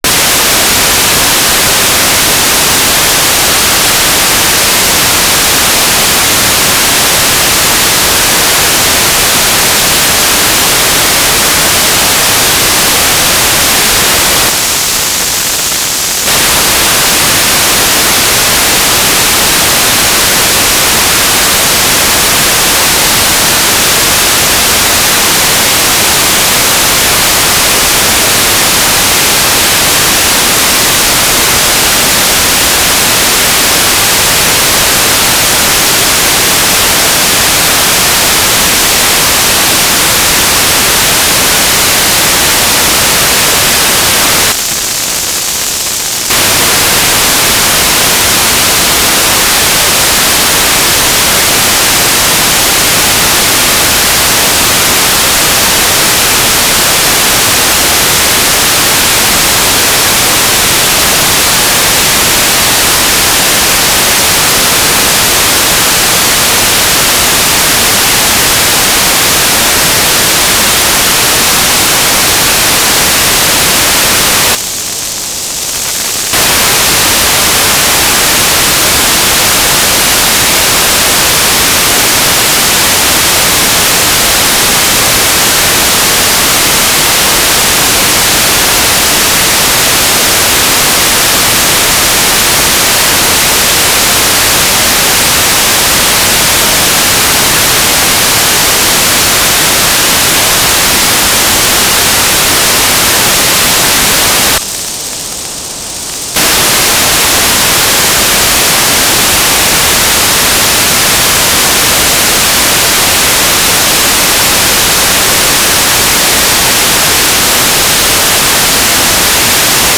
"transmitter_description": "Mode U - GMSK2k4 - USP",
"transmitter_mode": "GMSK USP",
"transmitter_baud": 2400.0,